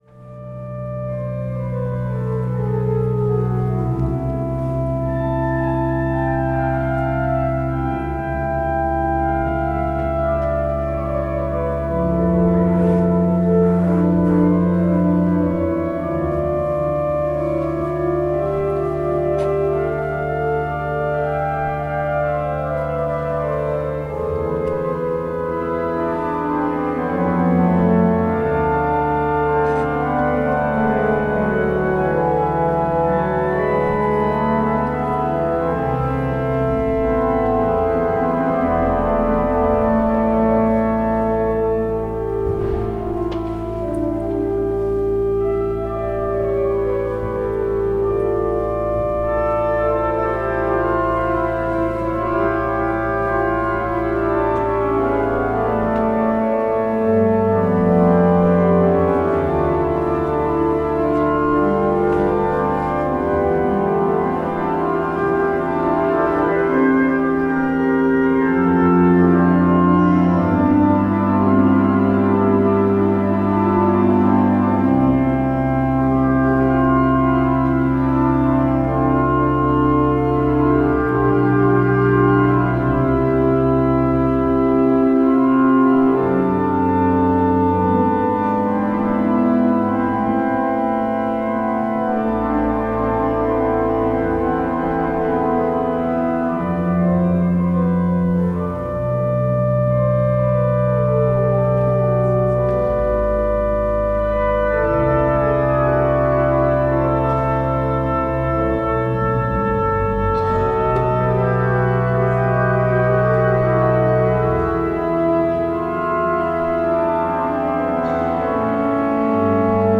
The organ of St. Michaelis in Hamburg plays a piece by Bach.